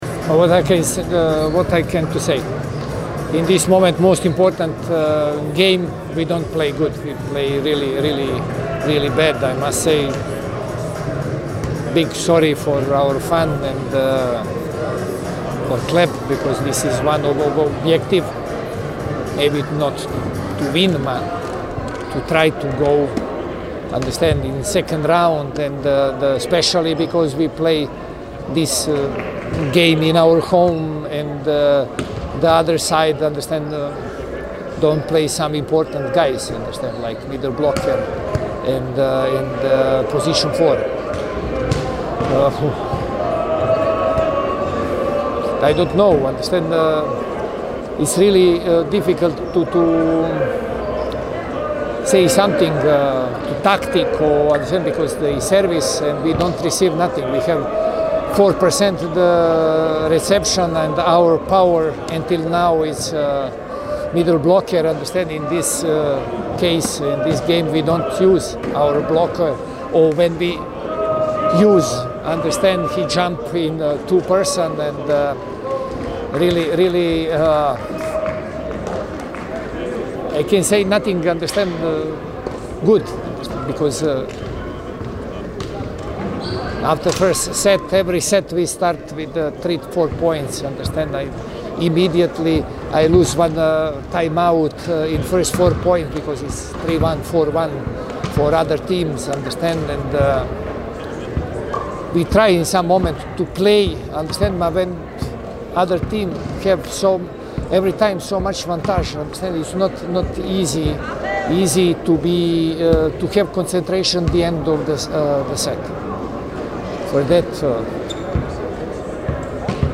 Siatkarze PGE Skry przegrali z Asseco Resovią 1:3 w 1/8 Pucharu Polski i odpadli z turnieju. W załączniku przesyłam wypowiedź trenera Slobodana Kovaca.